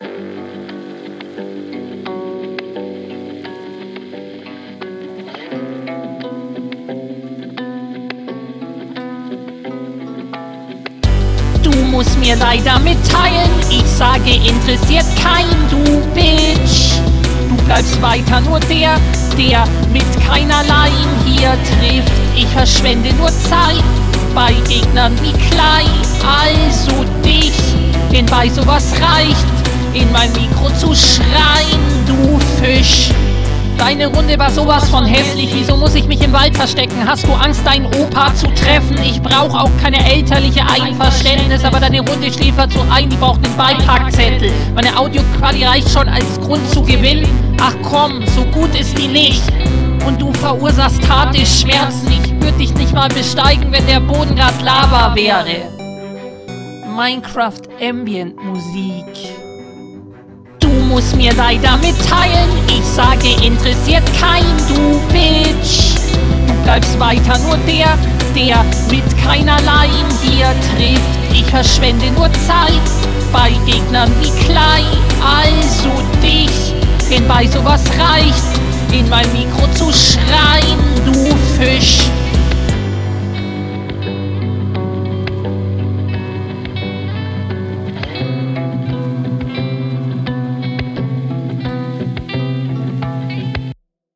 beste deiner runden. minimal auf dem takt, reime sind nice, hook ist furchtbar